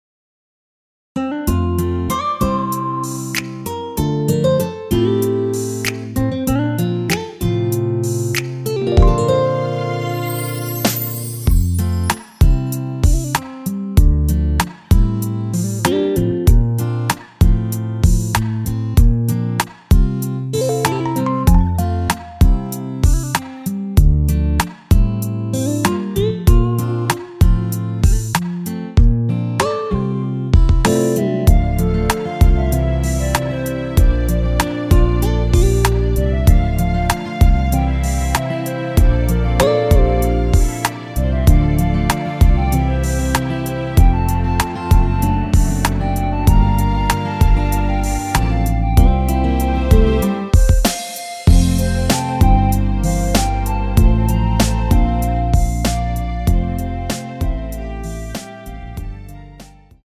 원키에서(-3)내린 멜로디 포함된 MR입니다.(미리듣기 확인)
◈ 곡명 옆 (-1)은 반음 내림, (+1)은 반음 올림 입니다.
앞부분30초, 뒷부분30초씩 편집해서 올려 드리고 있습니다.